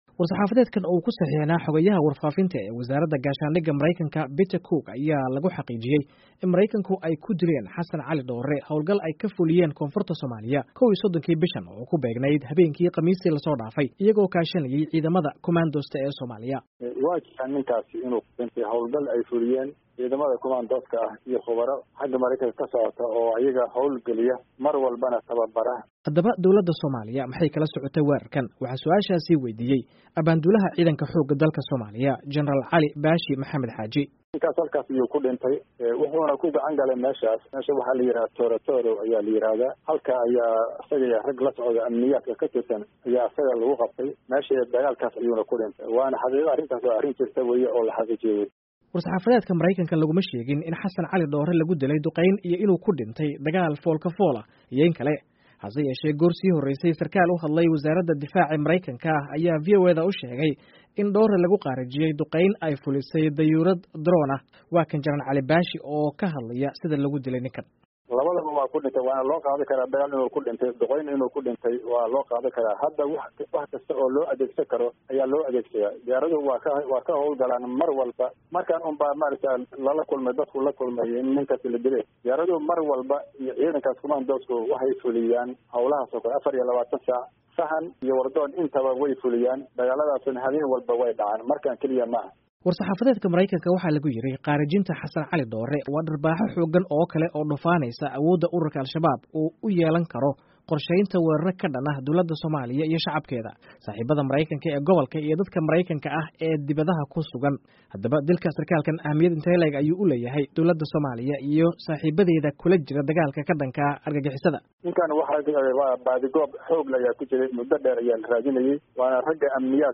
Warbixin Duqeyntii Lagu dilay Dhoore